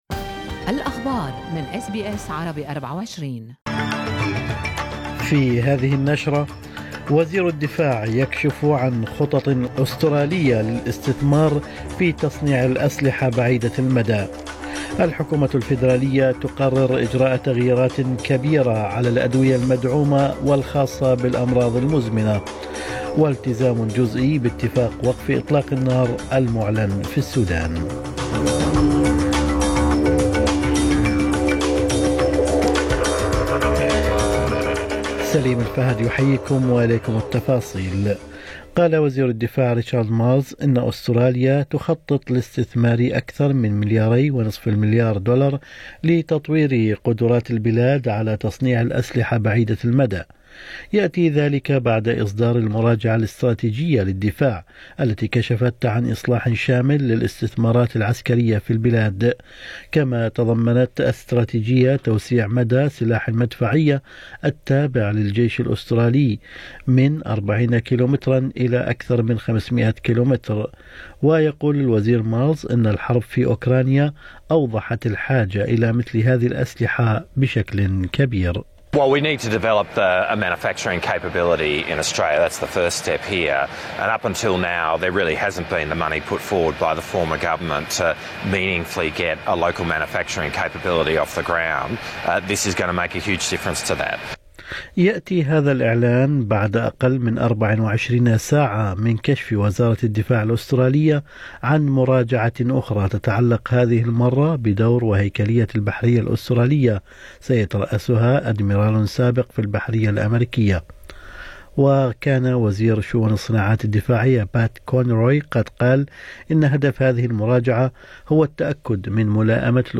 نشرة أخبار الصباح 26/4/2023